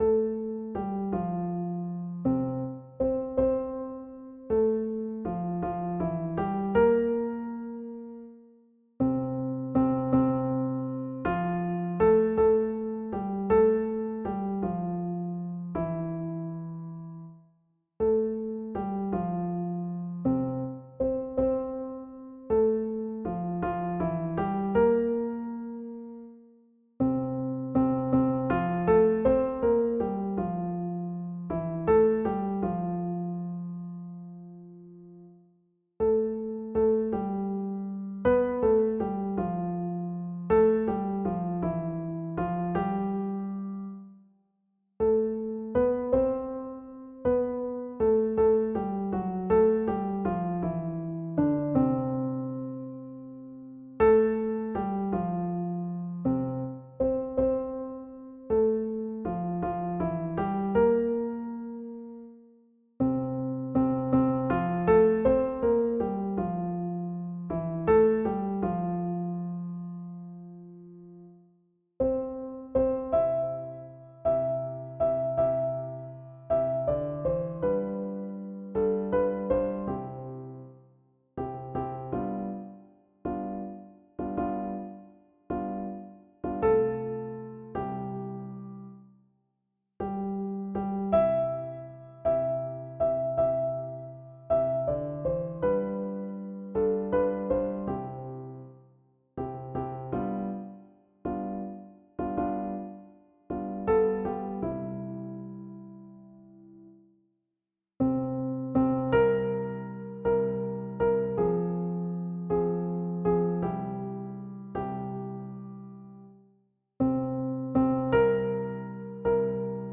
R�p�tition de la pi�ce musicale N� 606
Soprano